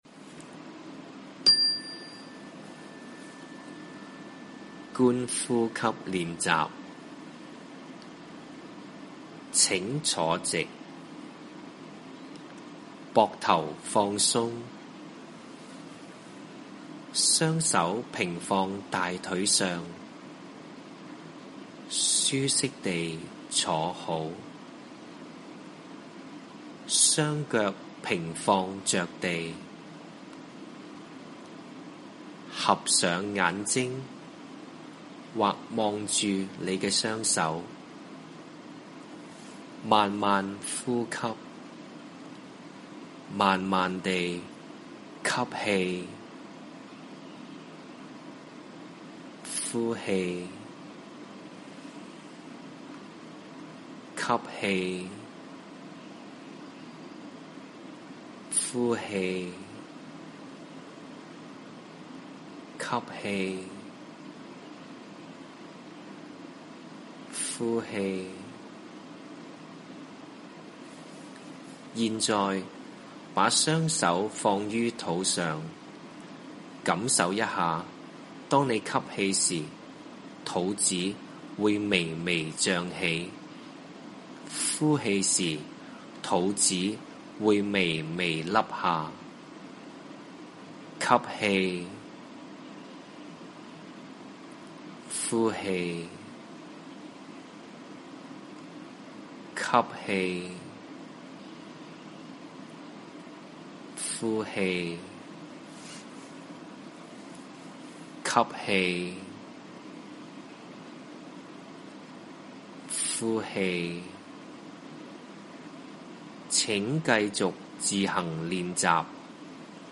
The Mindup "Core Practice" (Brain Break) 觀呼吸練習錄音聲帶 核心呼吸練習的步驟： 1. 仰臥挺胸，向下看或閉上眼睛 2. 聆聽鐘聲 3.
集中思考，再注意自己的呼吸 7. 再細心注意鐘聲發出的聲音 因文化及語言不同，此筆記可能與原作或有所差異 ☆ 學生每天早上班主任節及下午上課前各做一次觀呼吸練習，讓學生專注及保持平和的心情上課 ☆ 學生於午間活動時段可自由參與午間觀呼吸活動
觀呼吸練習錄音.mp3